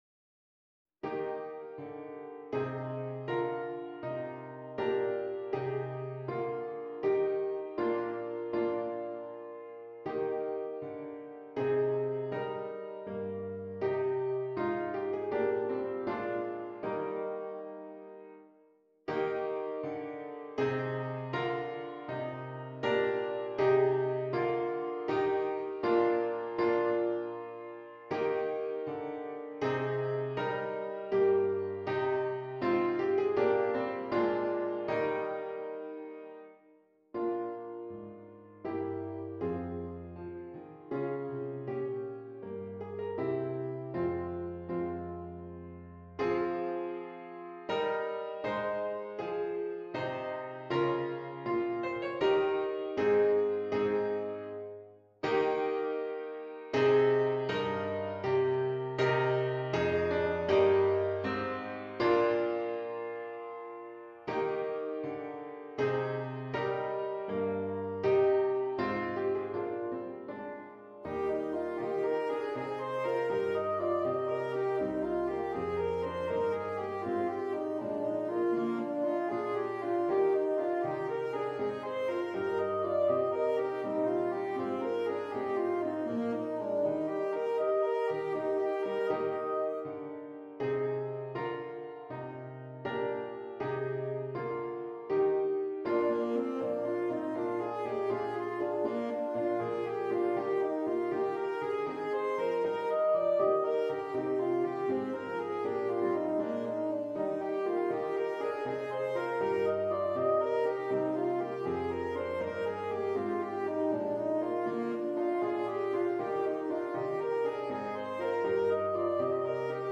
• Alto Saxophone and Keyboard